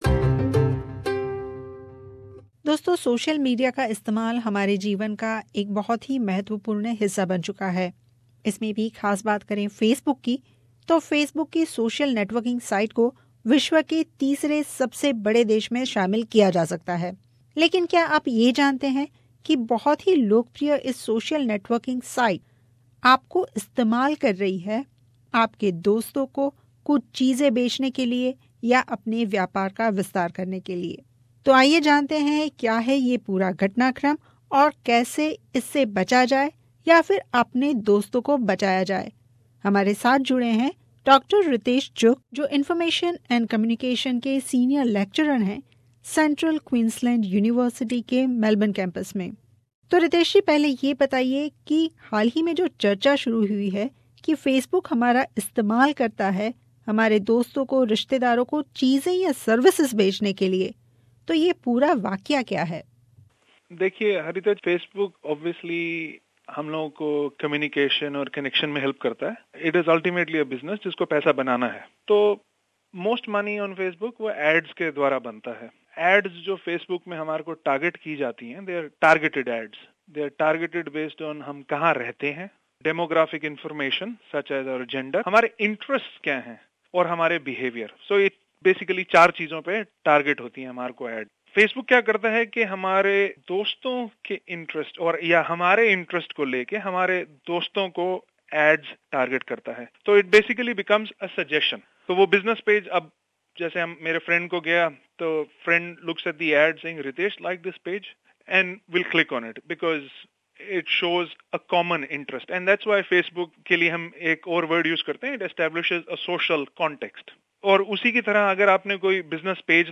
Fortunately, there is some remedy to the situation as the Apps and Adverts settings can be controlled on Facebook but most users are not aware of them. He outlined the changes that can be made to the settings, which can put us back in control of our privacy. Listen to the interview to find out how.